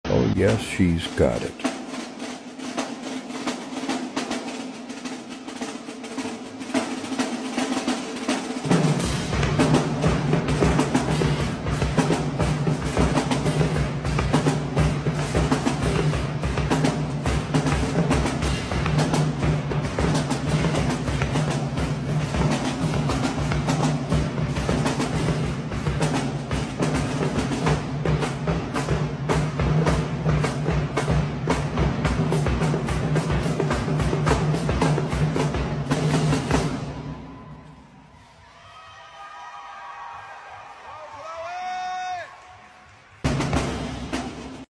We all know drumming is a male dominated thing so when you find a young lady that's 🔥🔥🔥.... you are sure to recognize her .